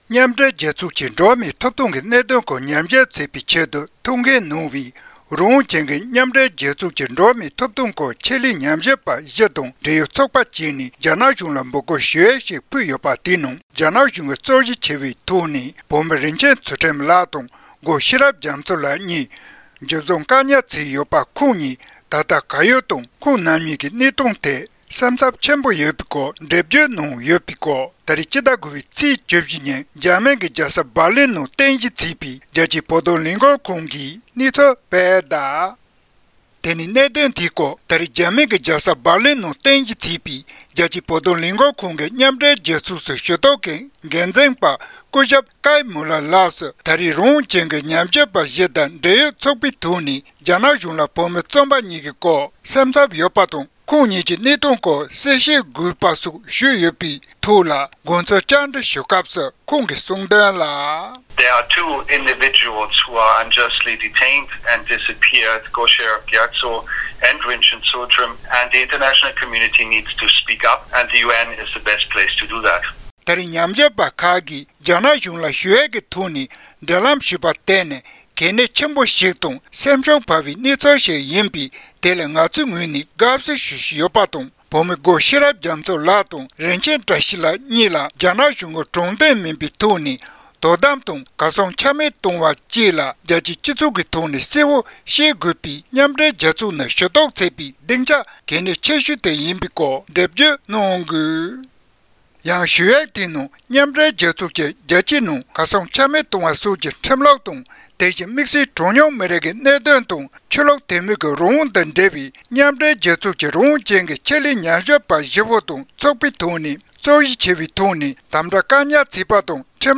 གསར་འགྱུར་དཔྱད་གཏམ